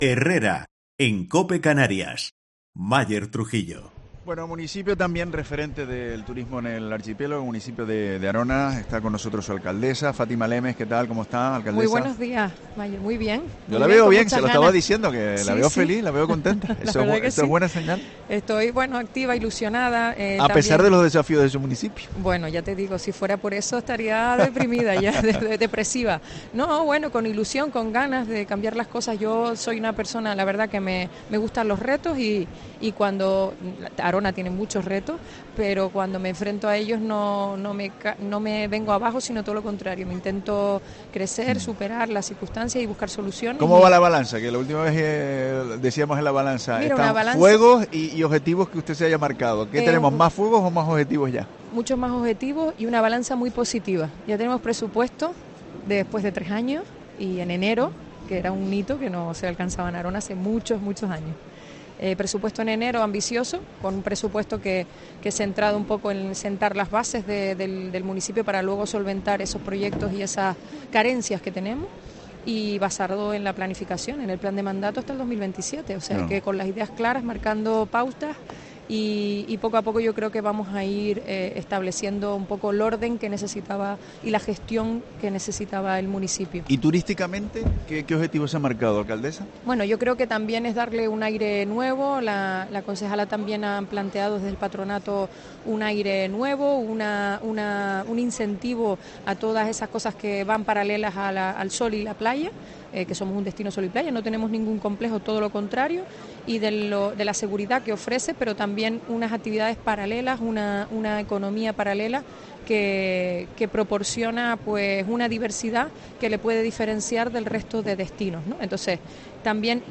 Entrevista a la alcaldesa de Arona, Fátima Lemes, en FITUR